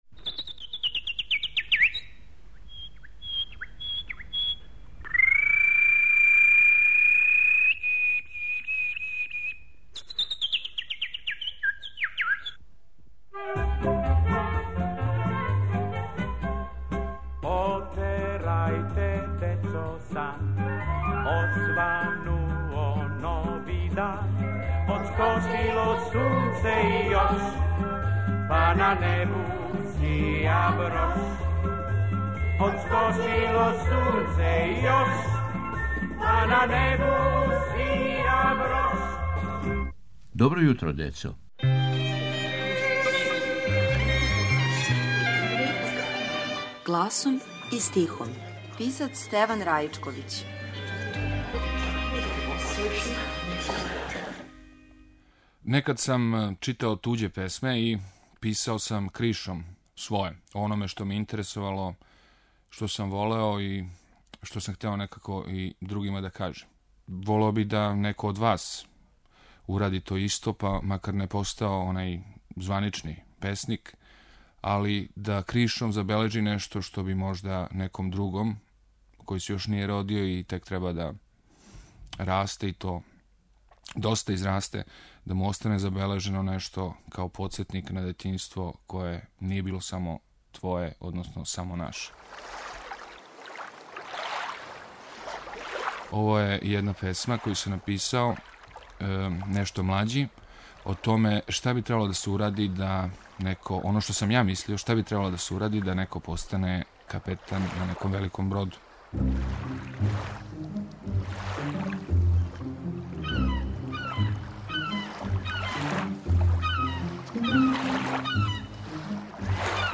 У новом серијалу под именом "Гласом и стихом" - из старе фиоке архиве Радио Београда, гласом и стихом, јављају се чувени песници за децу. Ово је јединствена прилика за слушаоце јер су у питању аутентични звучни записи које само Радио Београд чува у својој архиви. Ове недеље - књижевник Стеван Раичковић.